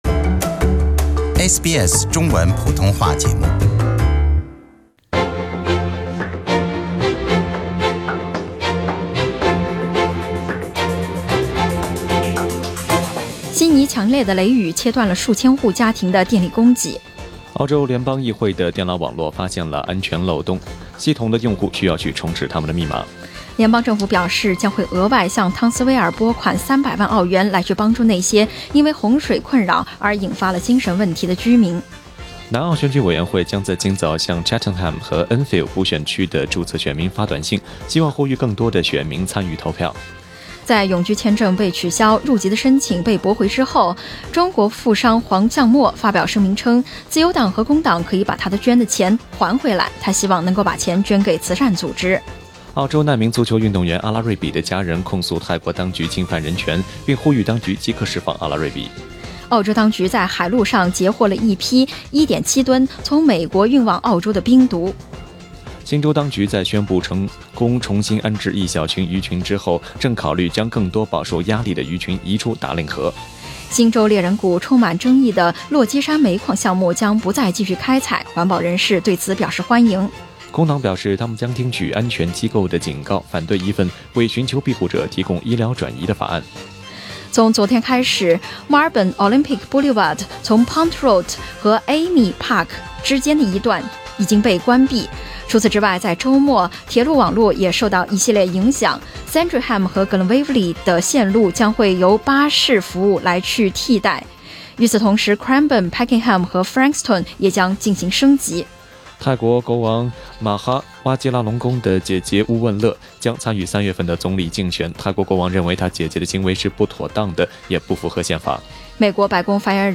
SBS早新聞 （2月9日）